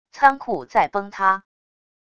仓库在崩塌wav音频